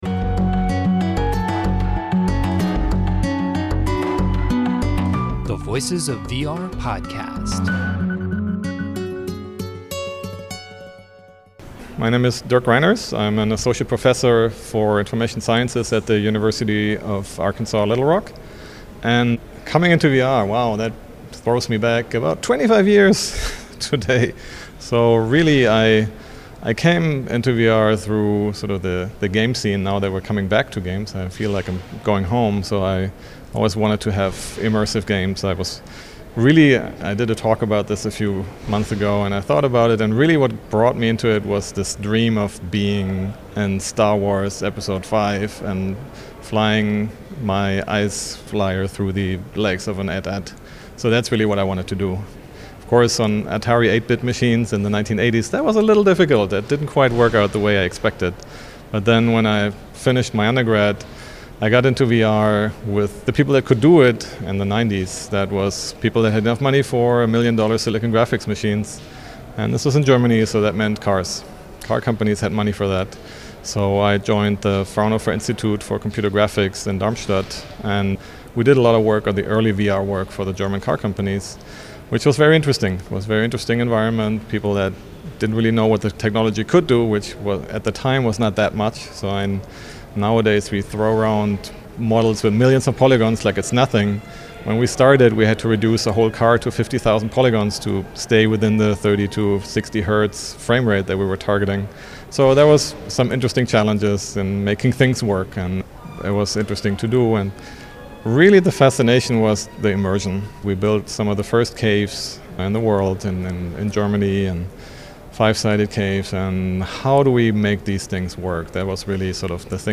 at IEEE VR